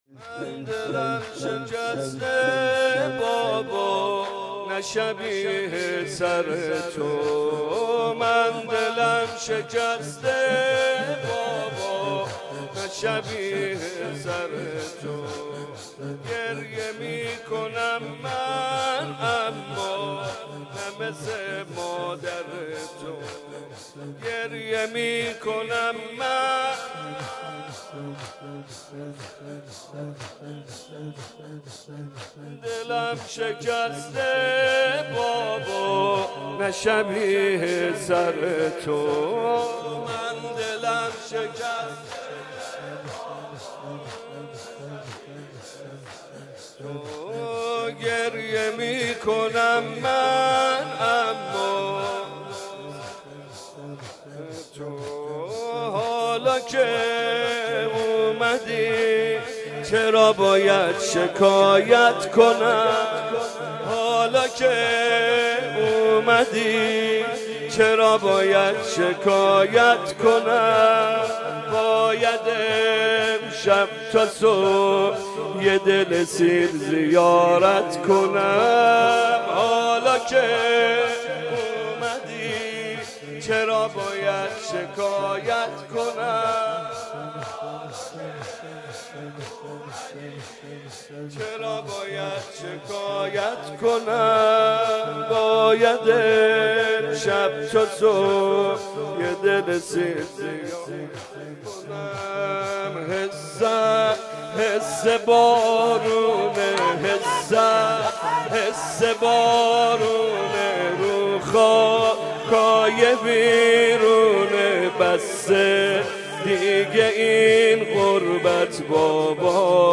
دانلود مداحی فاطمیه 1394
شور، شهادت حضرت زهرا(س)